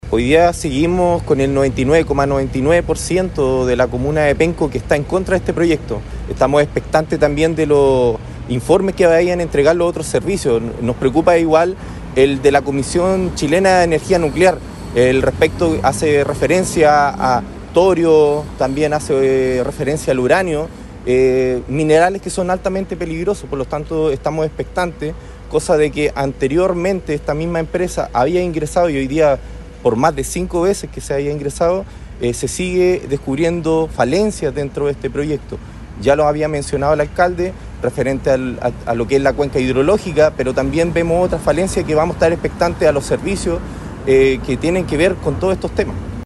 Su par Juan Manuel Viveros destacó que la oposición se basa en fundamentos técnicos y en la protección de la calidad de vida de los habitantes.